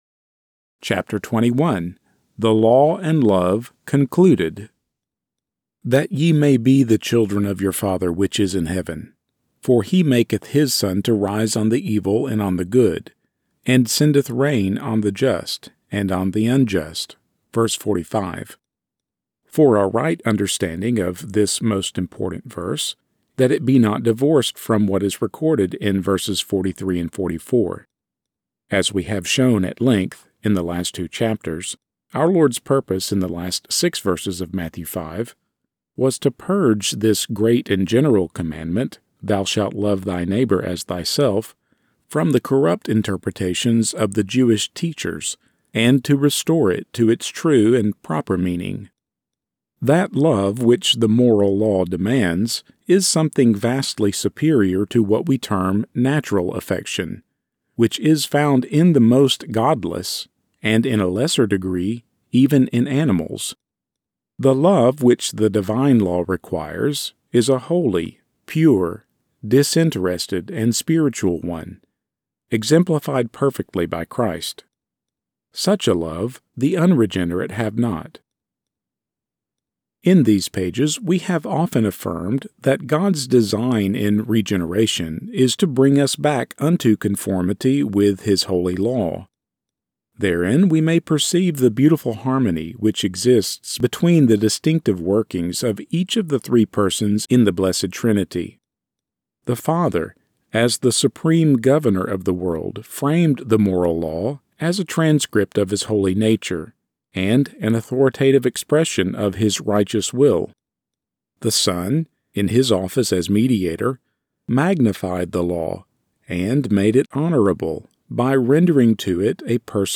Free Chapter from The Sermon on the Mount audiobook by Arthur W. Pink!